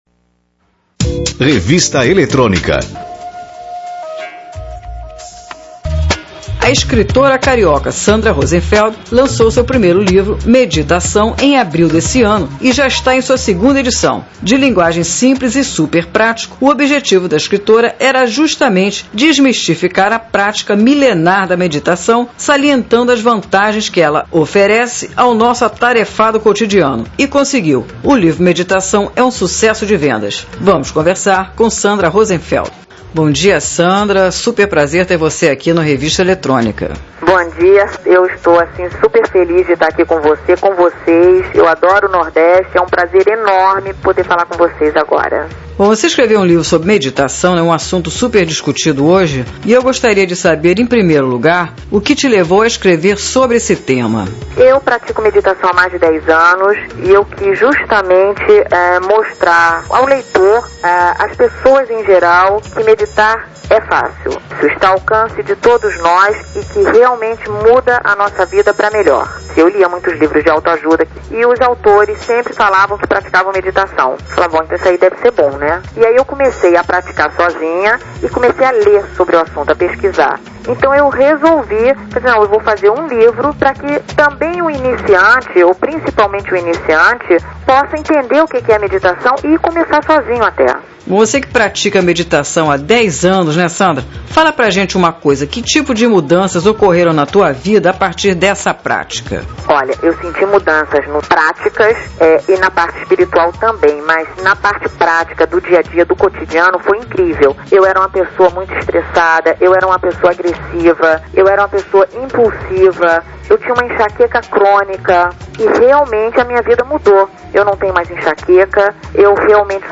Entrevista: Programa Revista Eletrônica Rádio CBN Recife - PE: Parte 1 (5 min.)